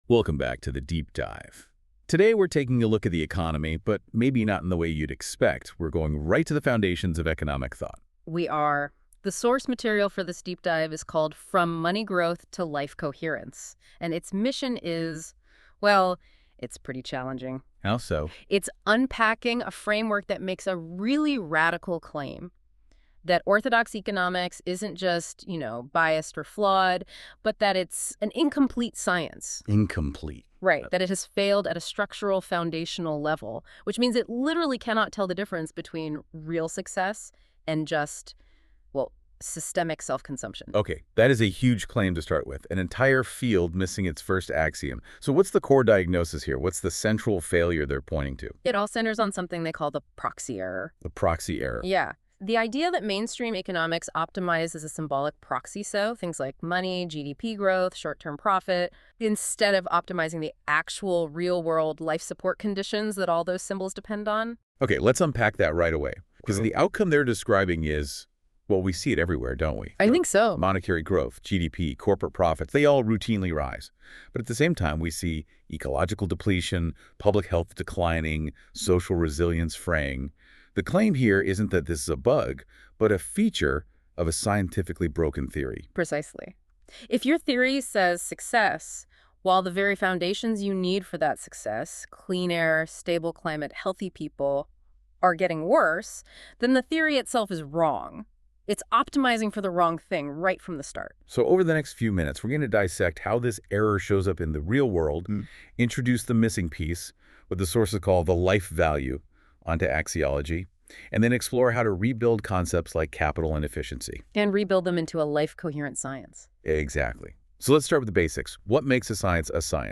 Deep Dive Audio Overview